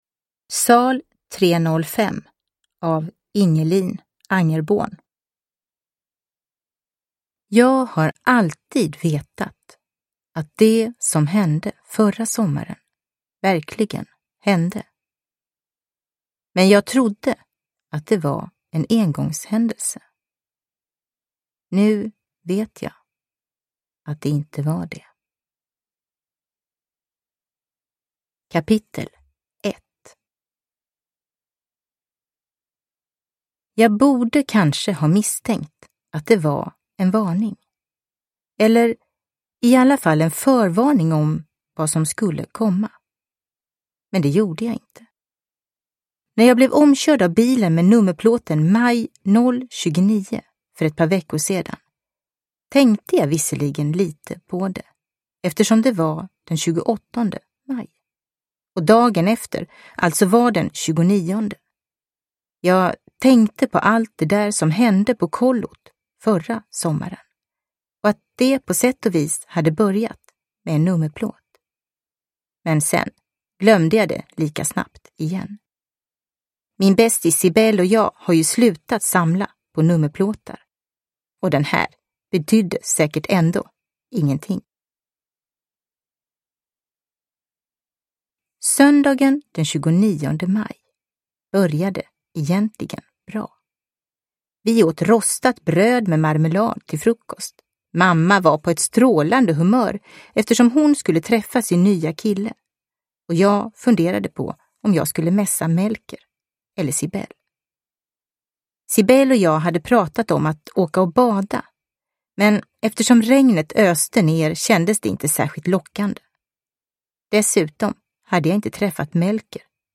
Sal 305 – Ljudbok – Laddas ner